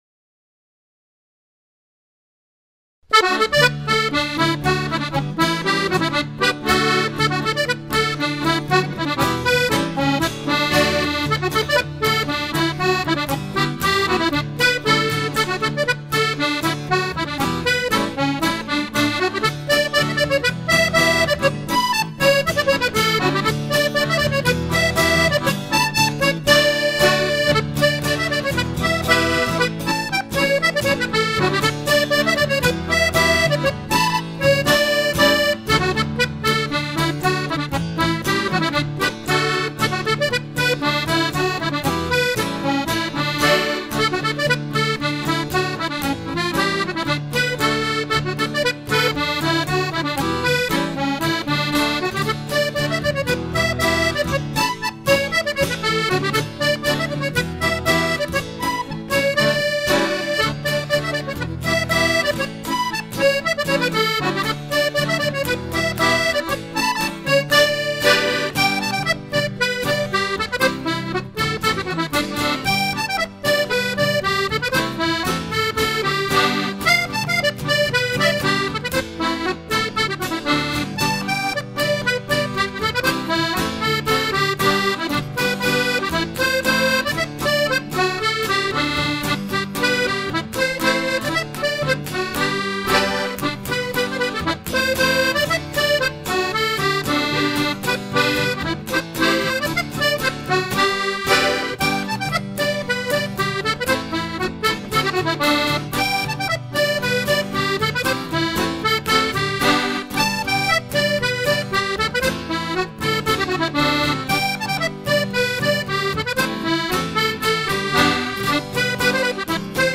polkas